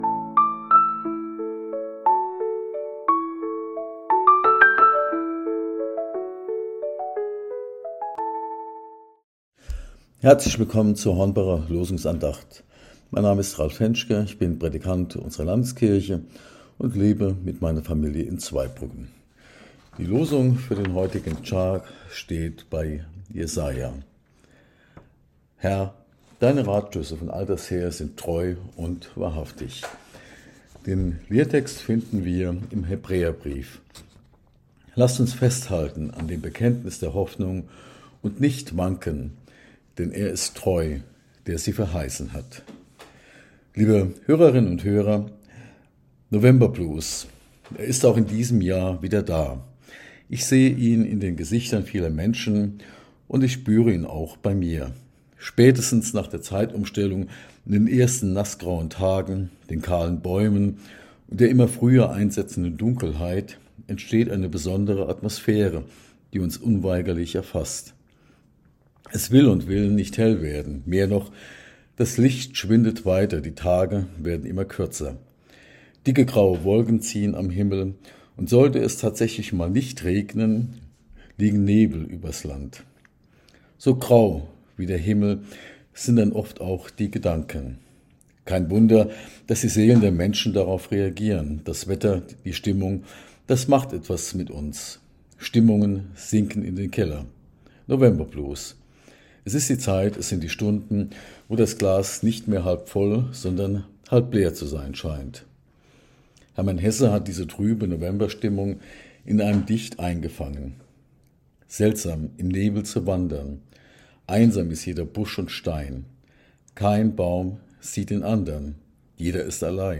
Losungsandacht für Mittwoch, 26.11.2025